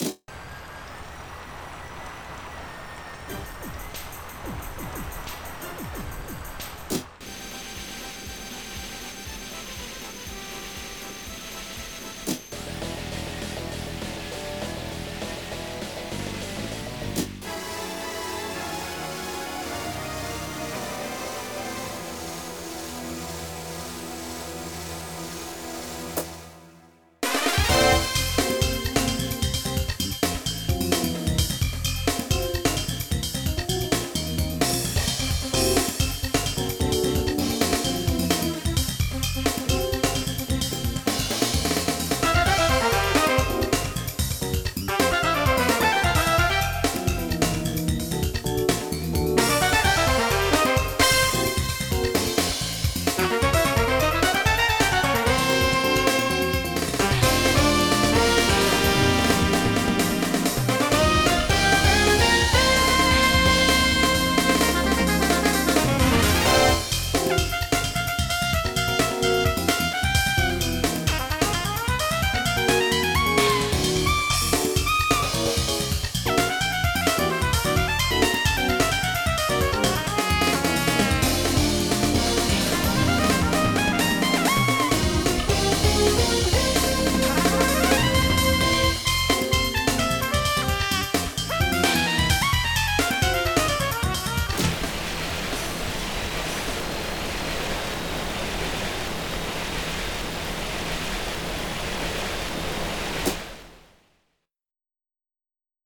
Jazz
Type General MIDI